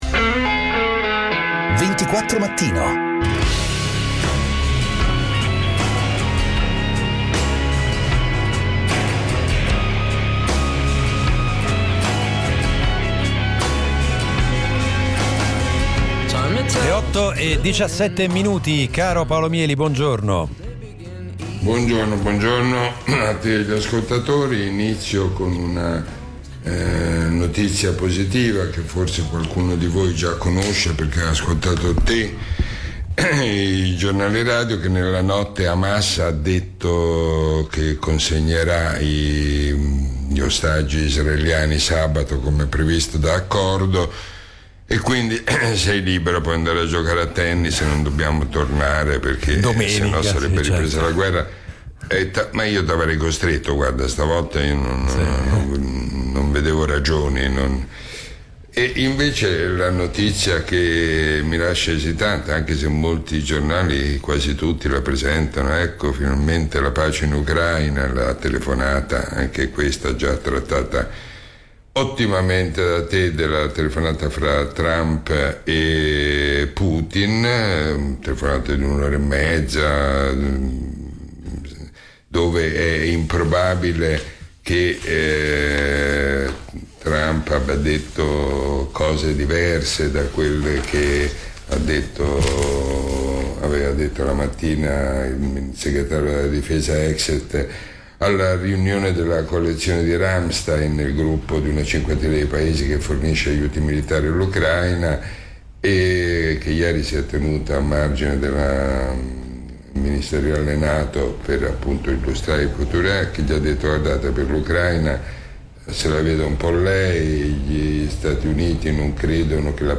Interviste Podcasts